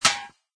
icemetal.mp3